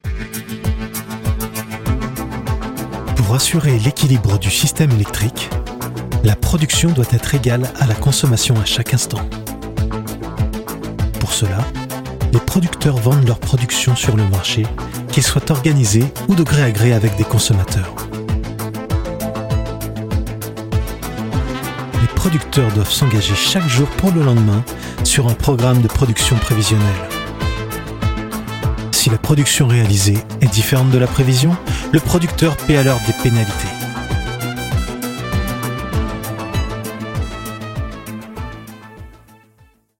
Bandes-son
30 - 60 ans - Baryton